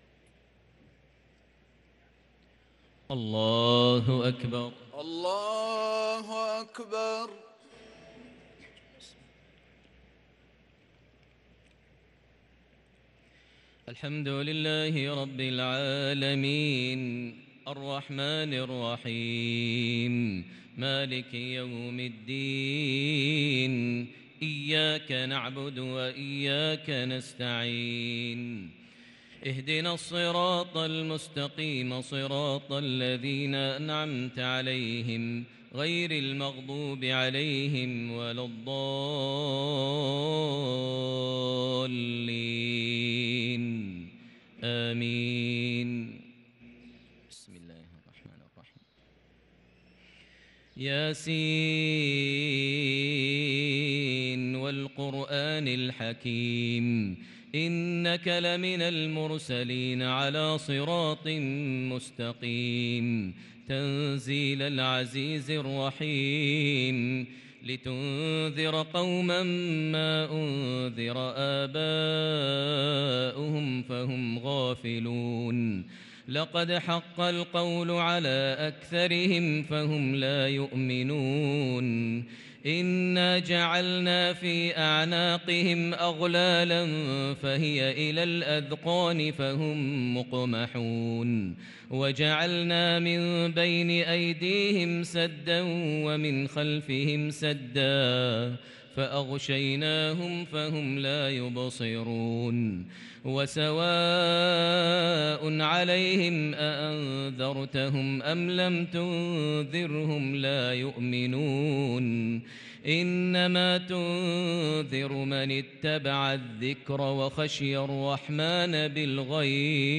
صلاة التراويح ليلة 29 رمضان 1443 للقارئ ماهر المعيقلي - التسليمتان الاخيرتان صلاة التهجد